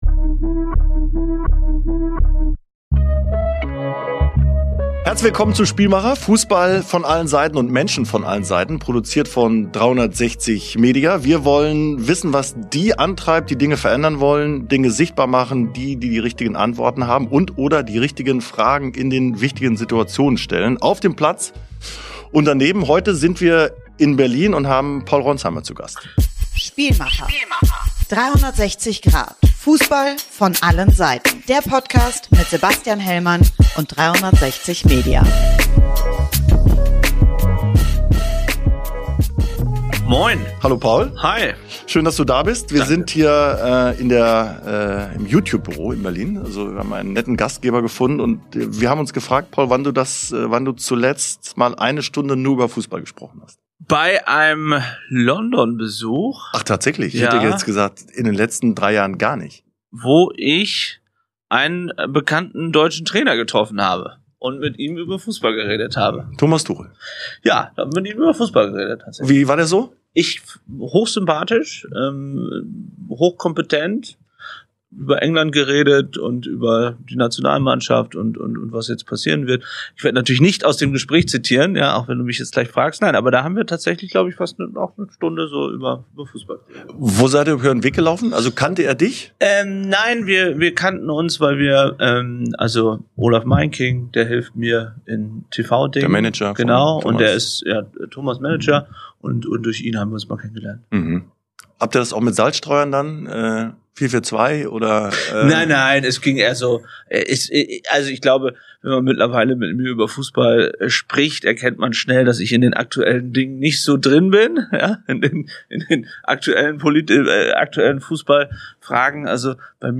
In dieser Folge ist Paul Ronzheimer zu Gast – Journalist, Kriegs- und Krisenreporter, stellvertretender Chefredakteur bei Bild.
In einem interessanten Gespräch mit Sebastian Hellmann geht es um diese Anfänge, um Vereinsleben und um die Kunst des Fragens – aber auch um die berühmten „Maulwürfe“ im Fußball und darum, wie Reporter seit jeher um Informationen ringen.